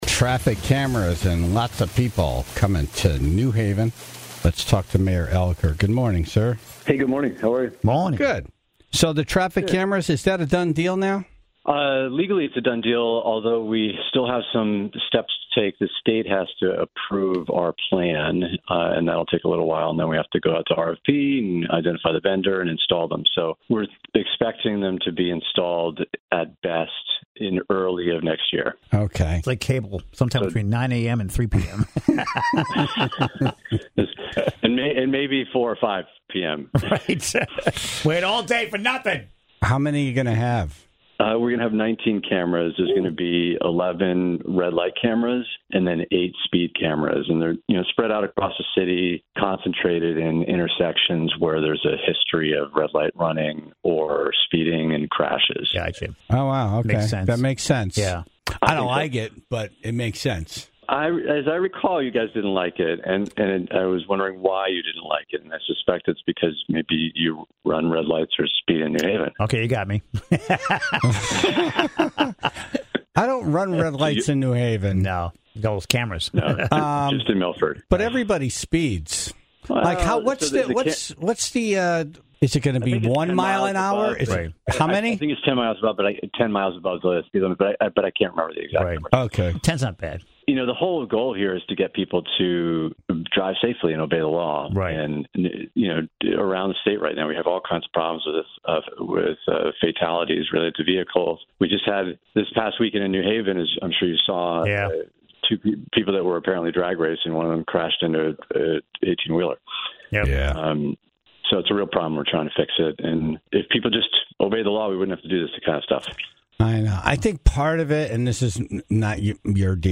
New Haven Mayor Justin Elicker talked about the new traffic cameras being installed in downtown New Haven for red light and speeding violations, and how effective they can be.